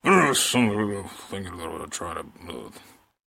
Звуки бормотания
Звук недовольного бормотания мужчины перед уходом